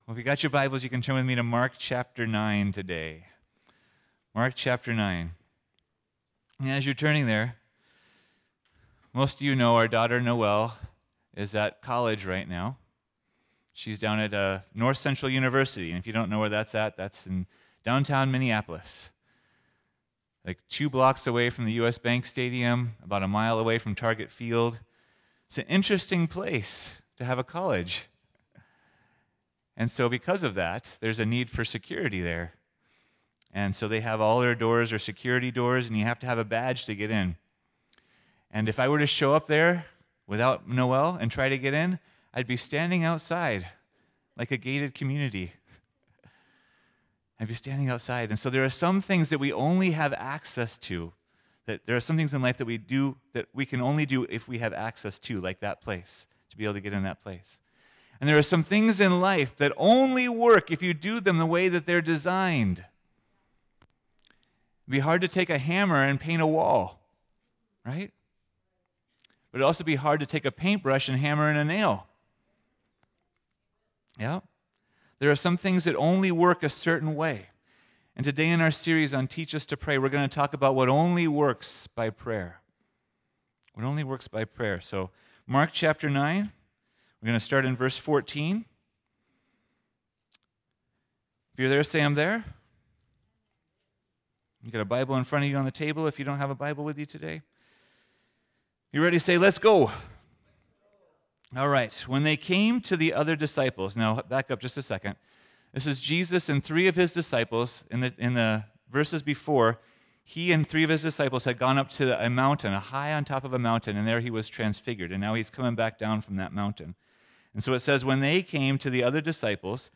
Teach Us to Pray – Only By Prayer – Friendship Church